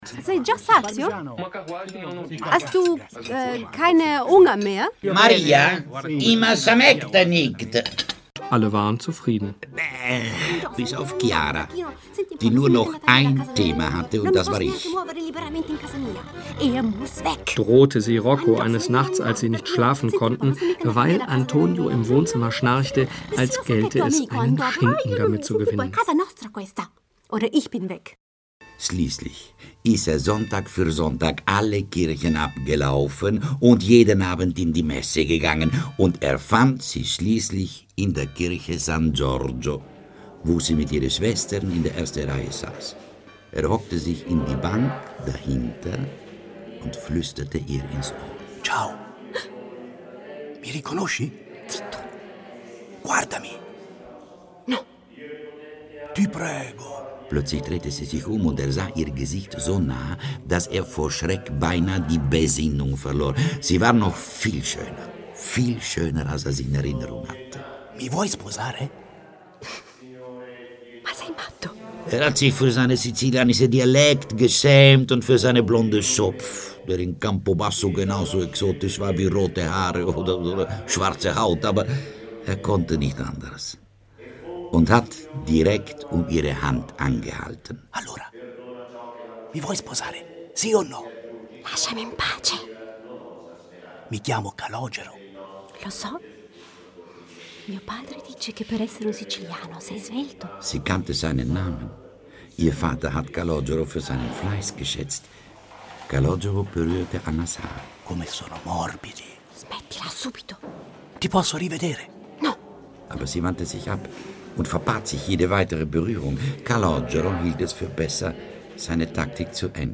Schauspielerin und Sprecherin, Muttersprache Italienisch, Deutsch mit F od. I Akzent, E und F mit I Akzent, breites Spektrum an Stimmlagen , Gesang.
Sprechprobe: Sonstiges (Muttersprache):
Native speaker (italian) and actress.Can speak german, french and english with french or italian accent.